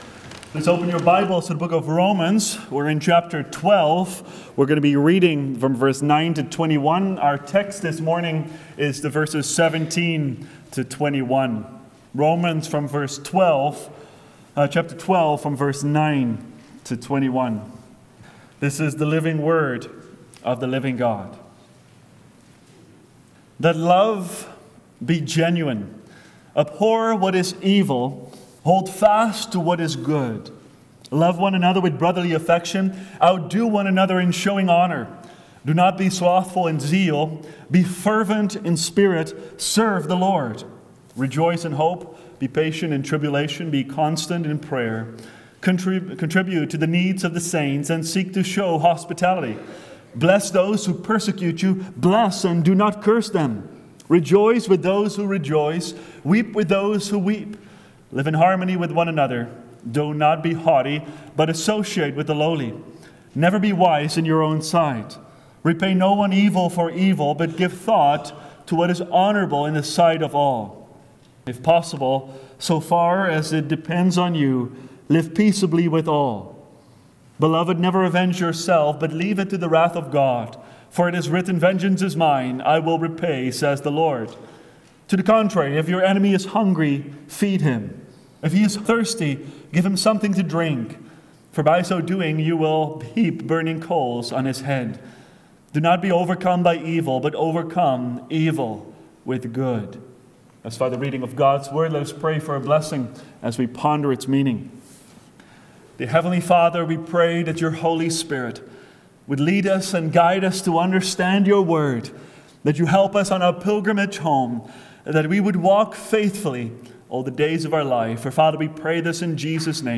Overcome Evil with Good – Seventh Reformed Church